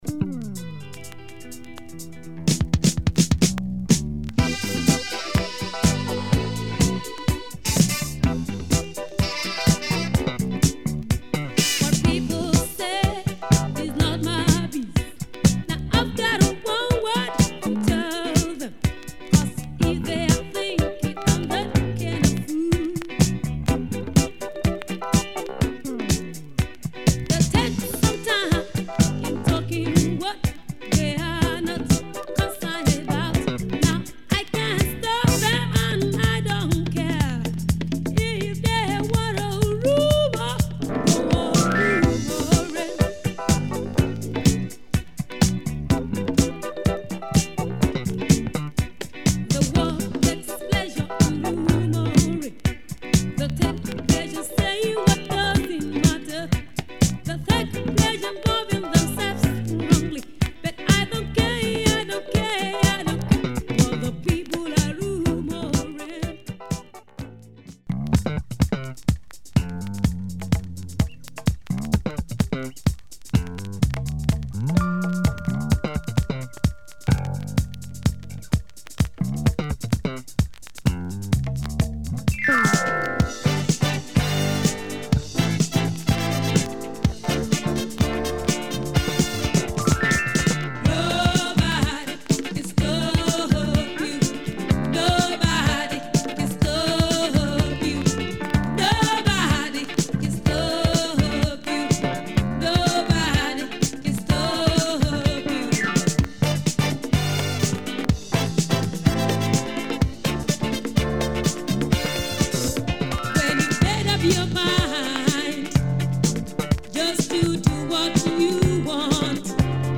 Killer Nigerian funk